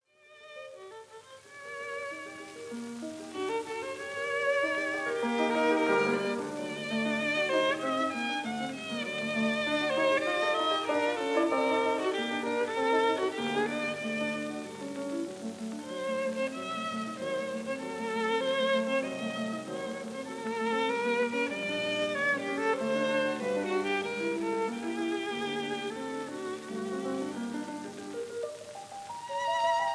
piano
Recorded in Zurich 1947